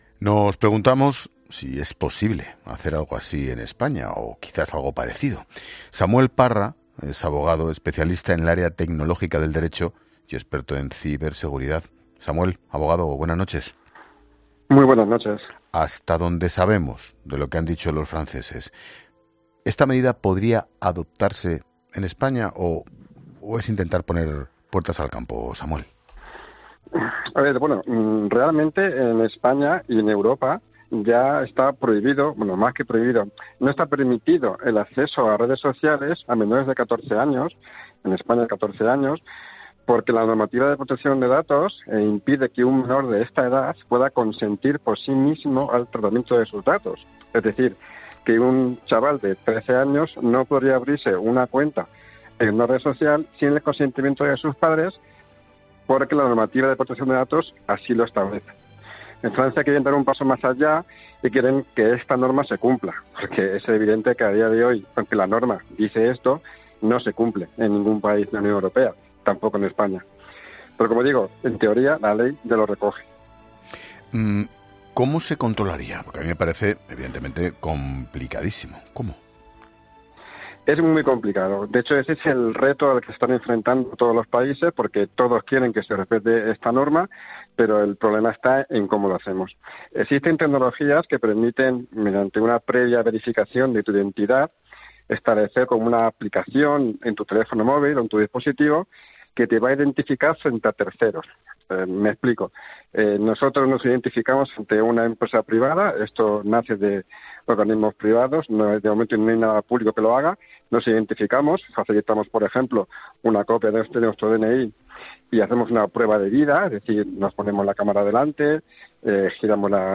abogado especializado en el área tecnológica del Derecho y experto en ciberseguridad, explica en La Linterna de COPE si esta medida podría aplicarse en nuestro país